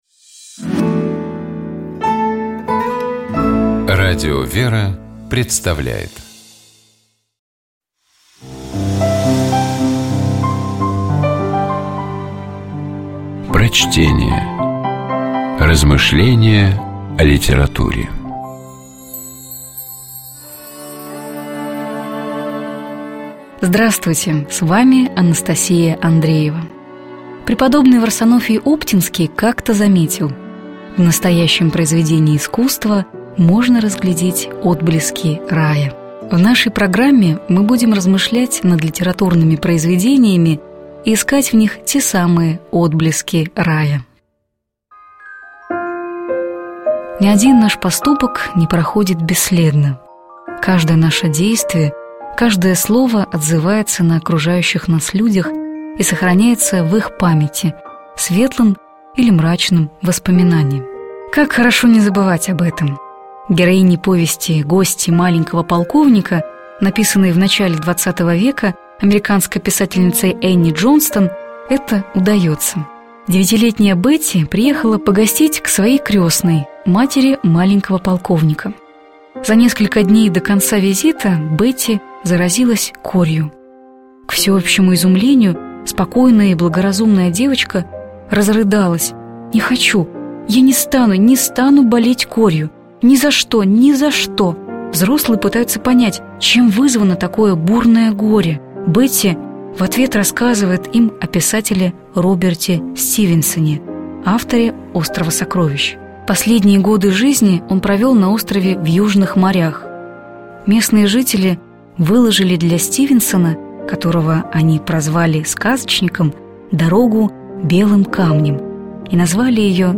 Prochtenie-Jenni-Dzhonston-Gosti-Malenkogo-polkovnika-Doroga-Ljubjashhego-Serdca.mp3